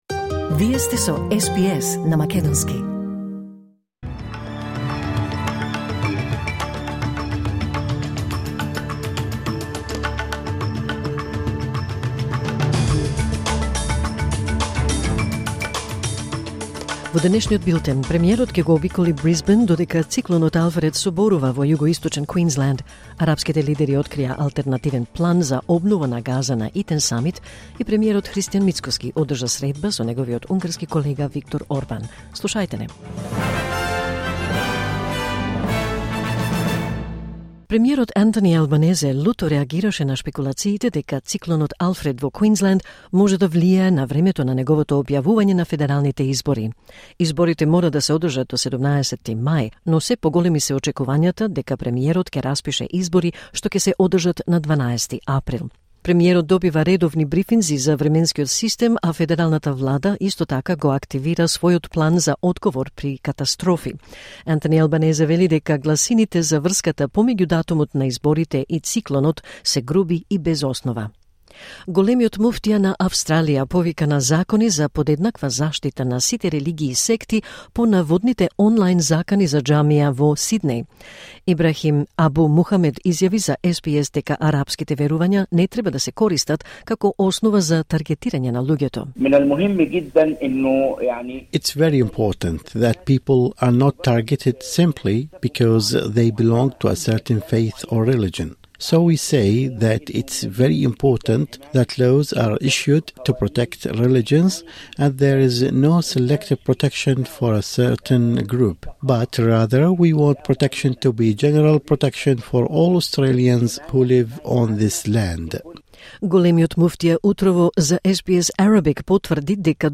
Вести на СБС на македонски 5 март 2025